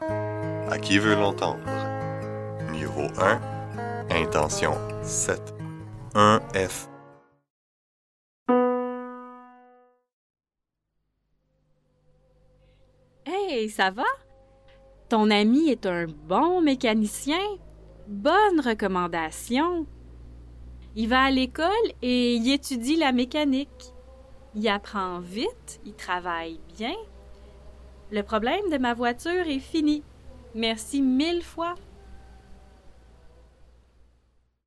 Pronoms personnels conjoints : Associer [i] devant un mot commençant par une consonne (sauf h muet) et [j] devant un mot commençant par une voyelle ou un h muet au pronom sujet il o